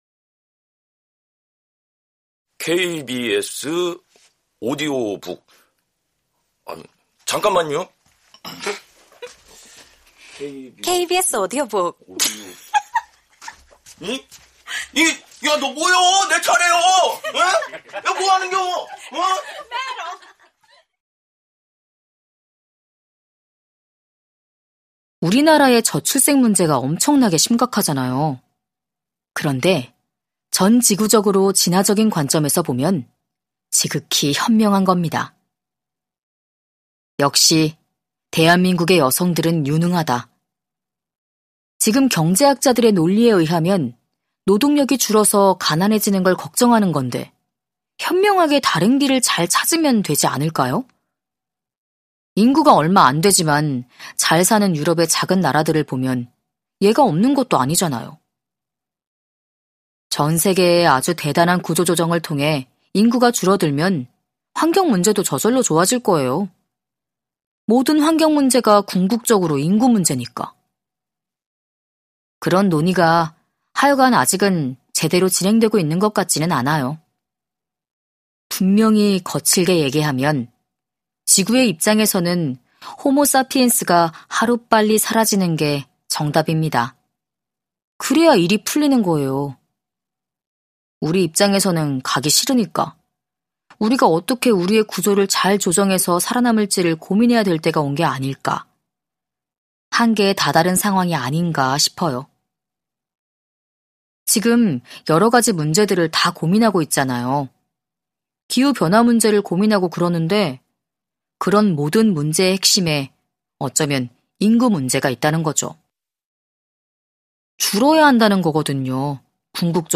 KBS 오디오북 - 최고의 클립